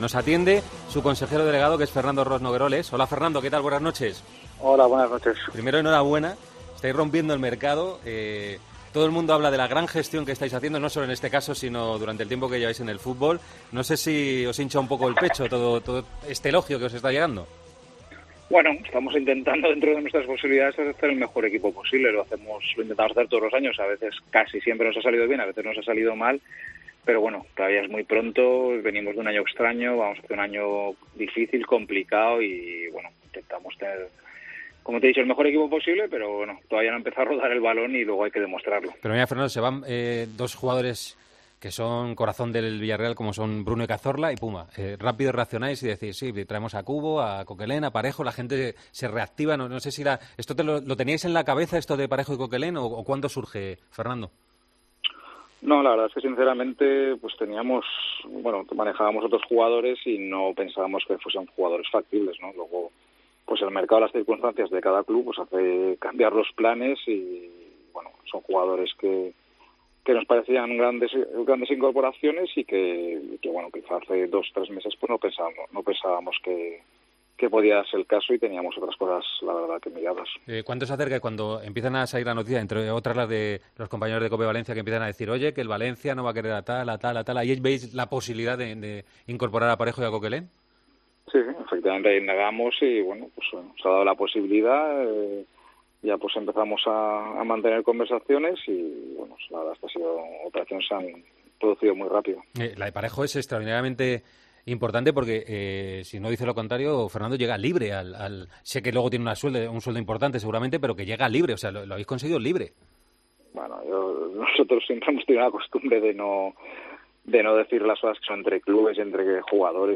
Entrevista a Fernando Roig en El Partidazo